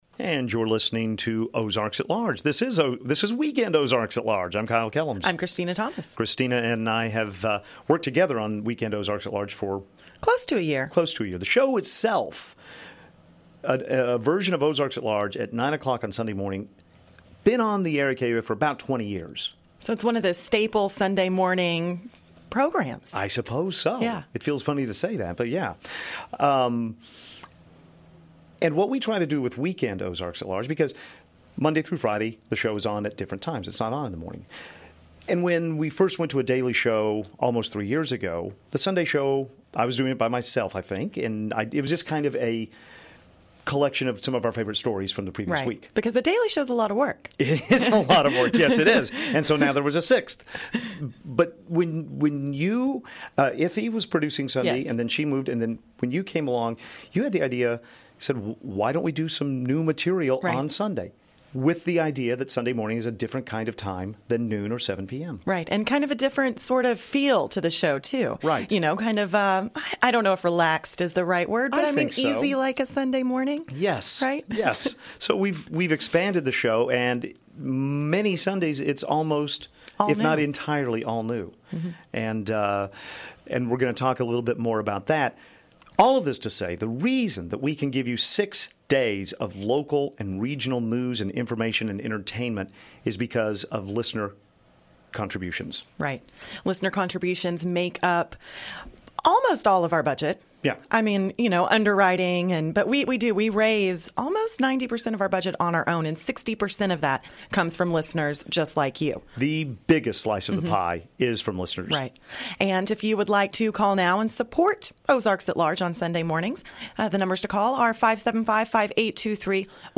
Ahead on this edition of Weekend Ozarks, a conversation with Senator Mark Pryor, and many different takes on the art of fundraising, engaging an audience and even attracting insects.